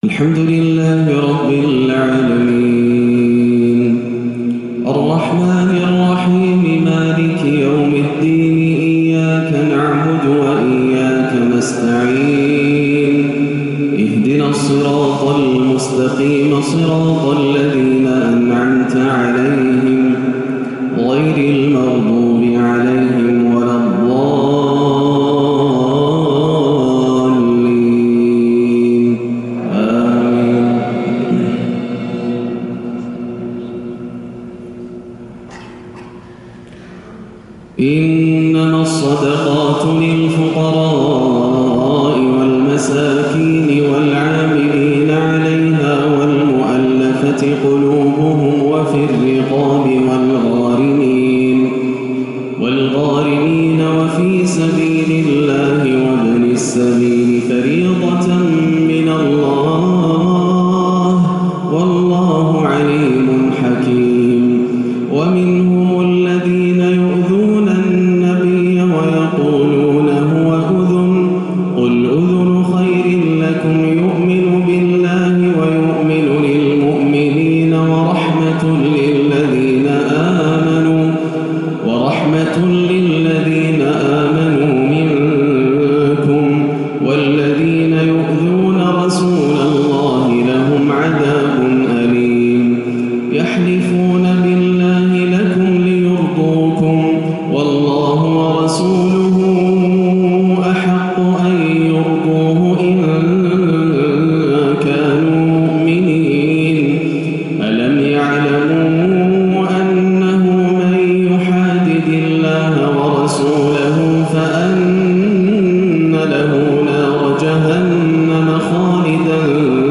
صلاة العشاء 9-2-1438هـ من سورة التوبة 60-72 > عام 1438 > الفروض - تلاوات ياسر الدوسري